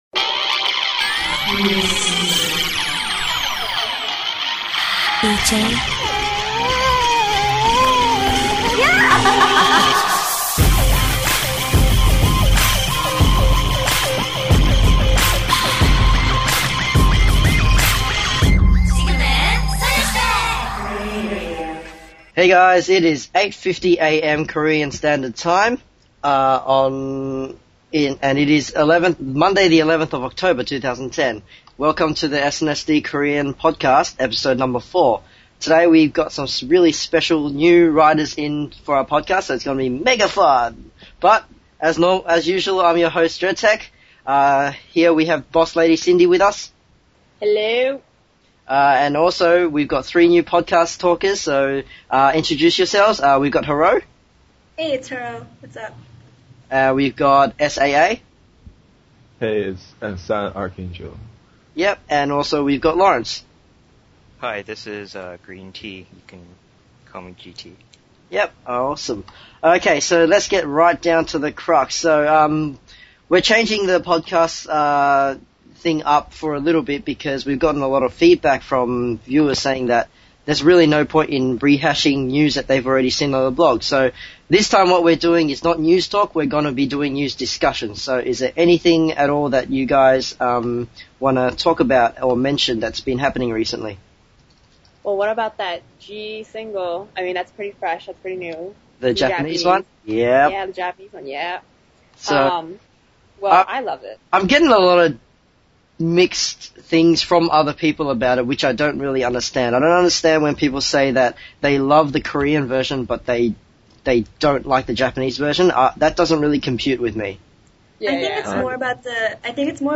And for the record, near the end during the…argument where afterward several podcast staff showed off their…inner talents, you have to realize the arguing went on for faaaaaaar more…but I didn’t want to bore you so I cut out a lot of that.